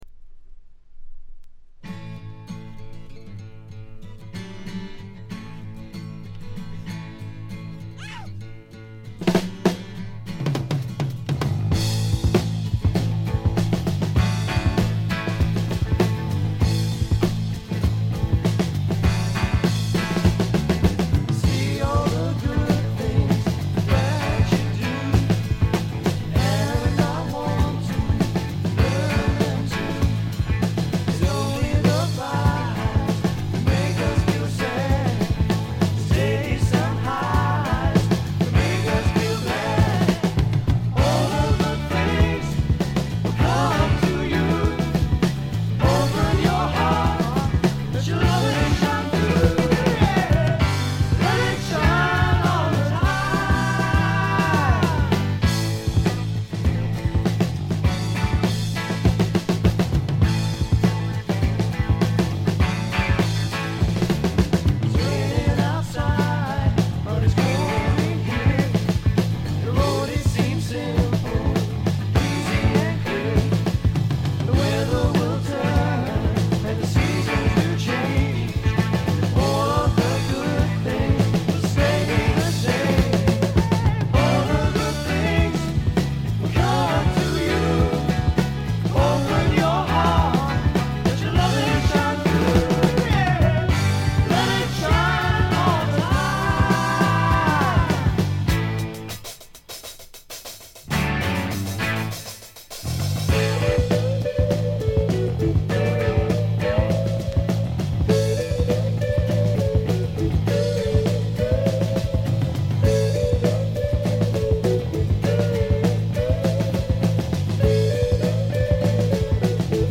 ほとんどノイズ感無し。
内容は枯れた感じのフォーク・ロック基調でちょっと英国スワンプ的な雰囲気もあり、実に味わい深いアルバムとなりました。
試聴曲は現品からの取り込み音源です。
Guitar, Piano, Violin, Vocals
Drums, Percussion